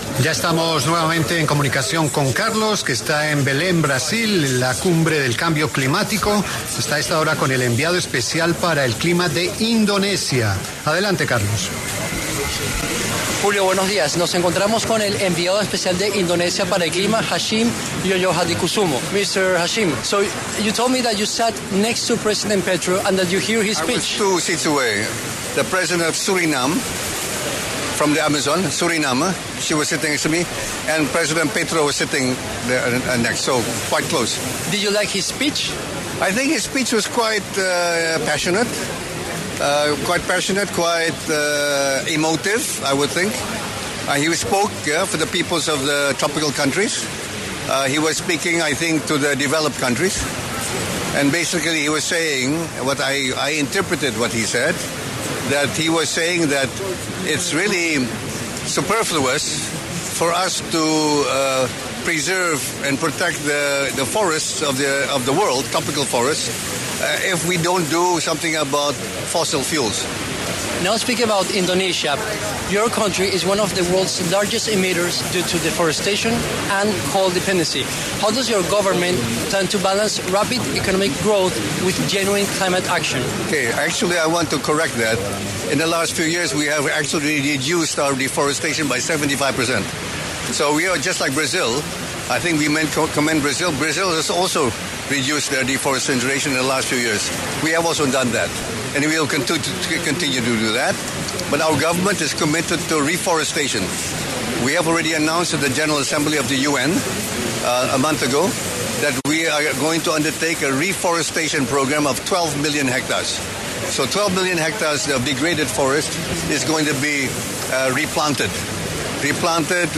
Hashim Djojohadikusumo, enviado especial para el Clima de Indonesia, conversó con La W acerca de la COP30 y también el discurso que dio el presidente de Colombia, Gustavo Petro.